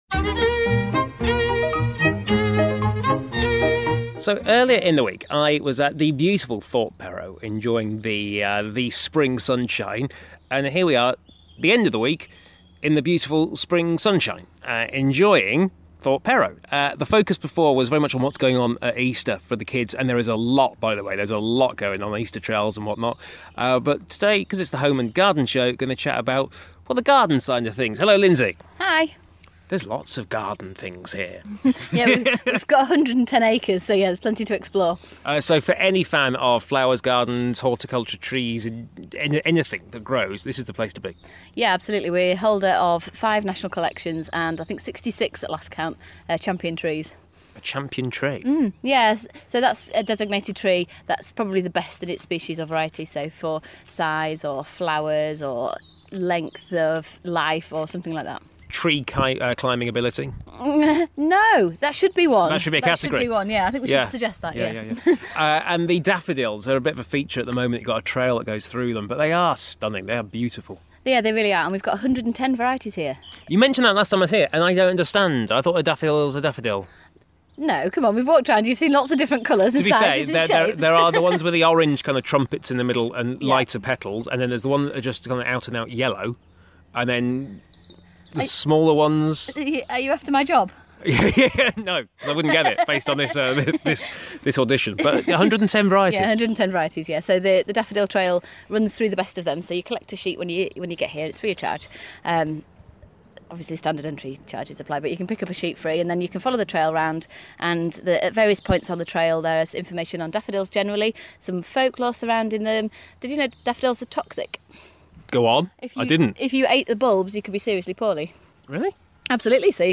Birds are tweeting, the daffs are out, the trees are award winning